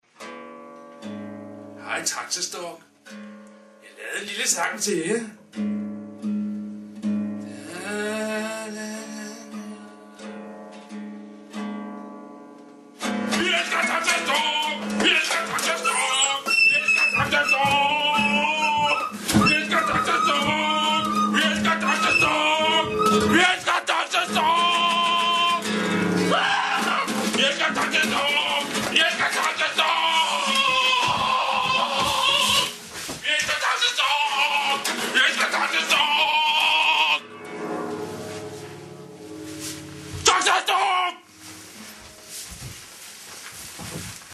det svedigste danske Metal band!